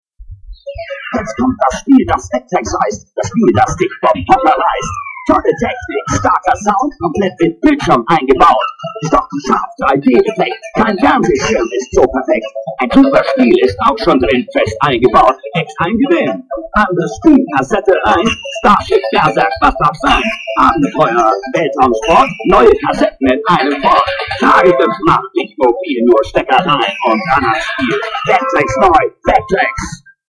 MB Vectrex German Audio Commercial
Great audio commercial in German.
vectrex_commercial.mp3